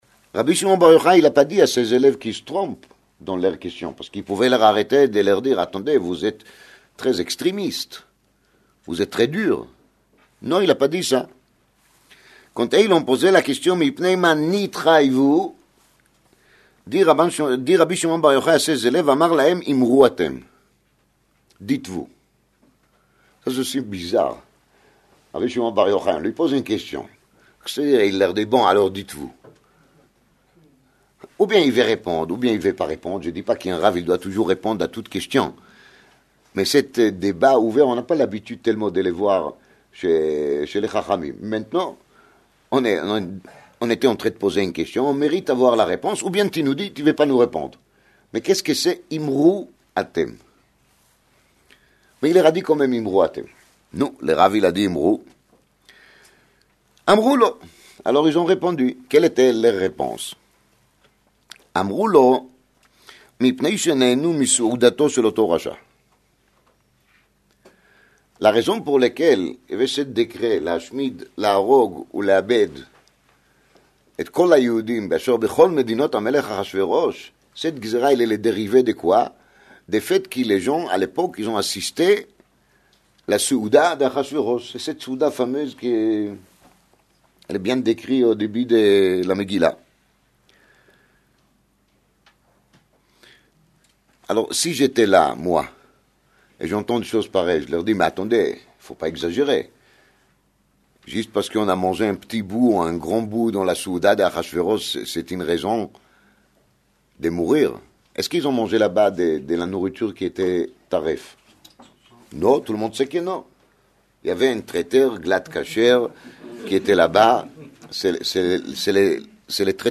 01:16:46 Cours a été donné Motsé Shabbath peu avant Pourim 2008, alors que 3 jours avant un terroriste est entré dans une Yeshivah à Yeroushalayim et a assassiné 8 Ba’hourim durant leur étude de la Torah.